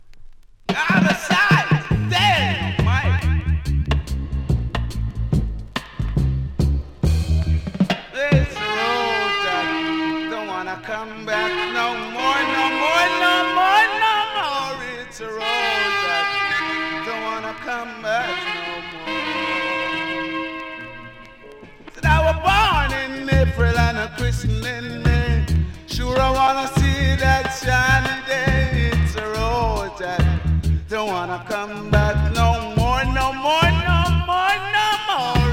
REGGAE 70'S
キズそこそこあり、多少ノイズもありますがプレイは問題無いレベル。